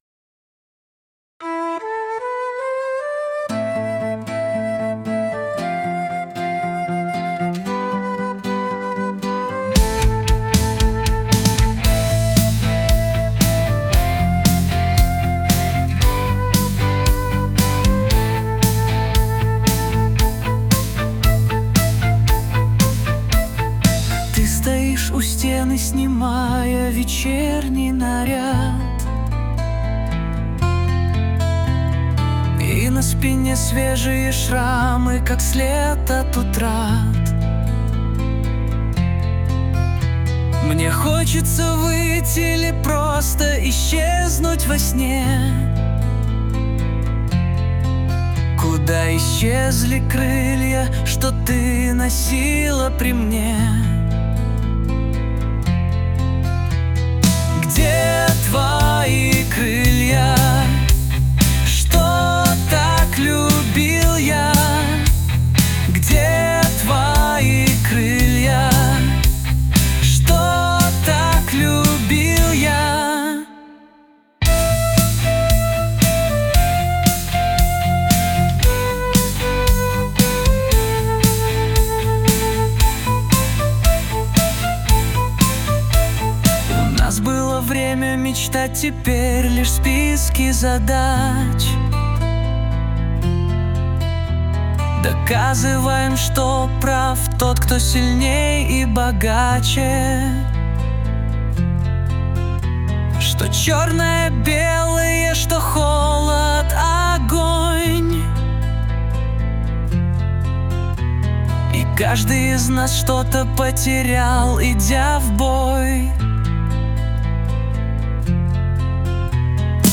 RUS, Caver, Romantic, Lyric, Rock, Indie | 03.04.2025 20:54